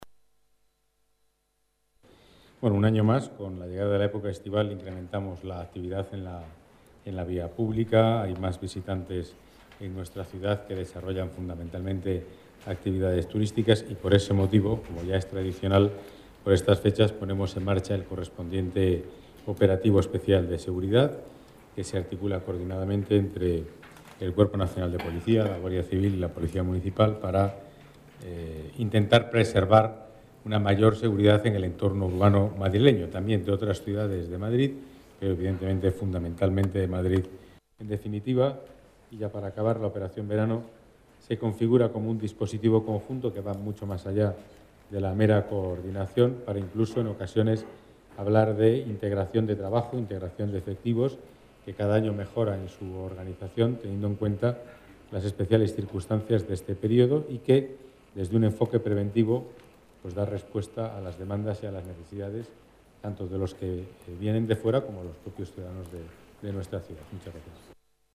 Nueva ventana:Declaraciones de Pedro Calvo